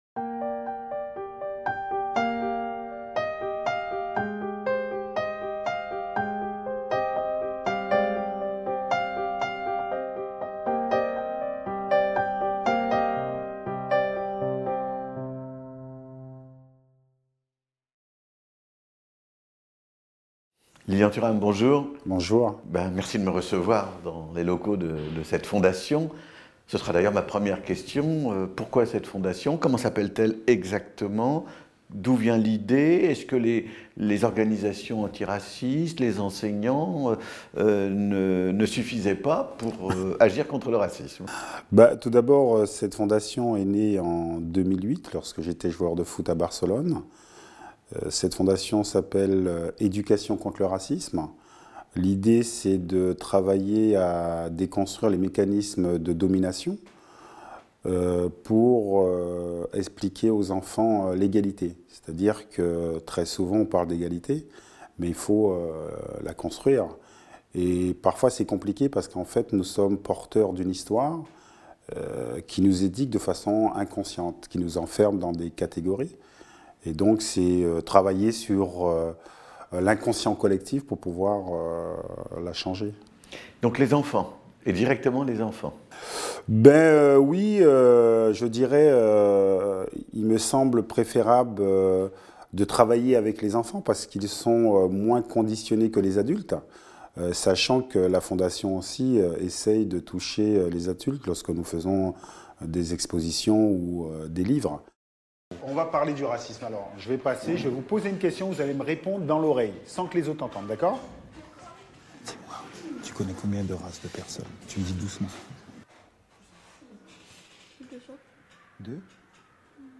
L'éducation pour lutter contre le racisme et antisémitisme - Un entretien avec Lilian Thuram | Canal U